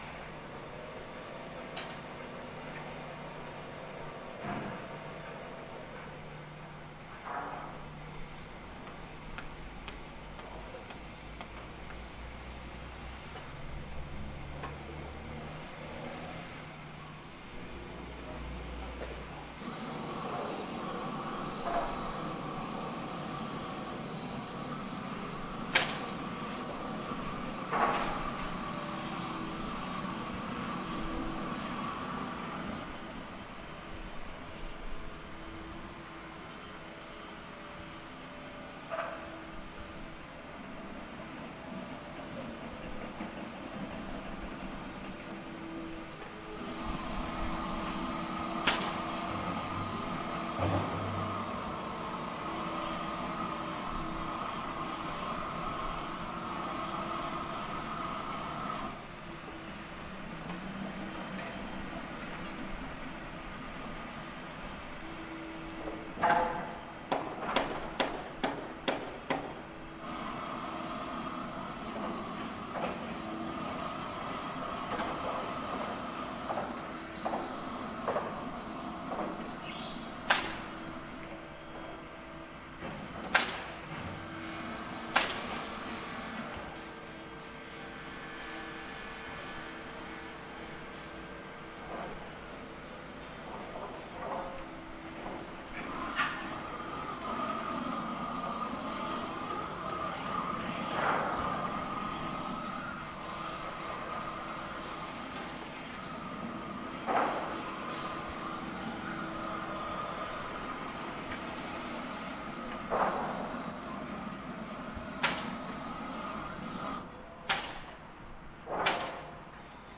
环境 " 有燃烧器的施工现场 2
描述：施工现场，射钉枪、远处的圆锯和火焰喷射器在前景中粘贴柏油垫。
标签： 现场录音 建设 火焰运动员 现场施工
声道立体声